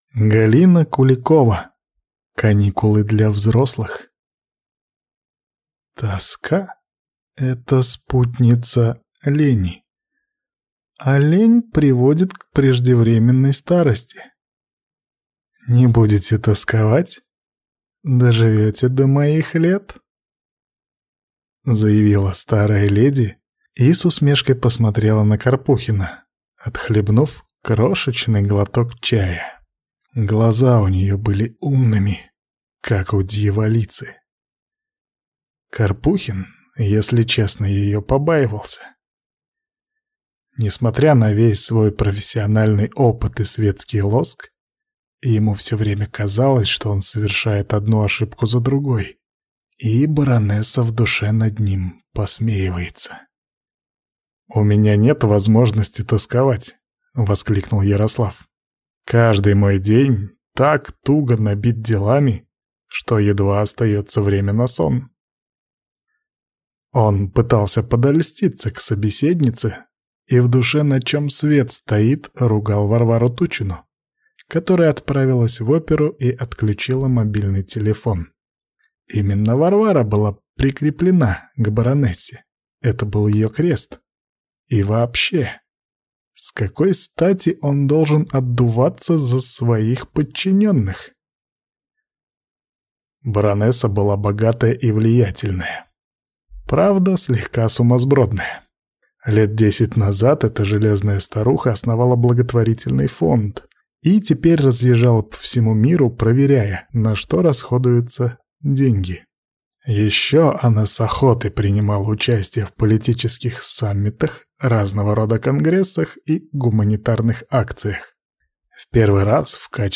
Аудиокнига Каникулы для взрослых | Библиотека аудиокниг